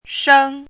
“苼”读音
shēng
国际音标：ʂəŋ˥;/ʐuei˧˥
shēng.mp3